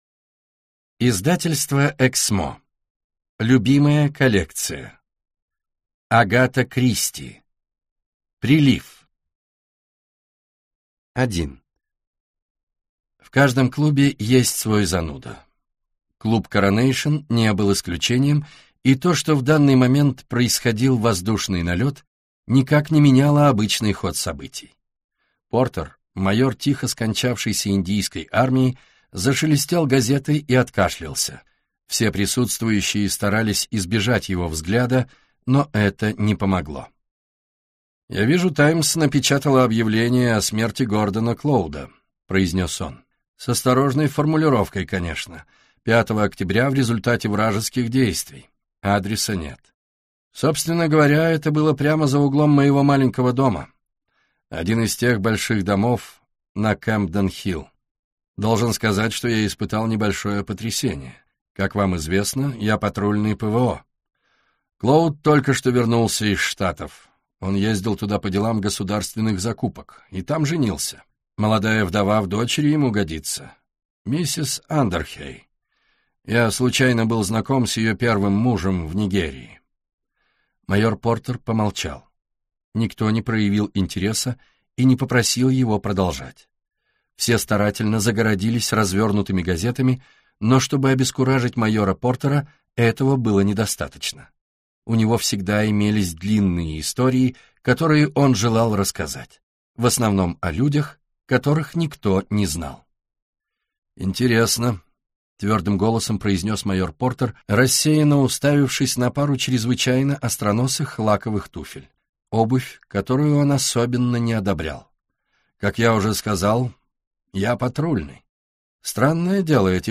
Аудиокнига Прилив - купить, скачать и слушать онлайн | КнигоПоиск